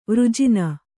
♪ vřjina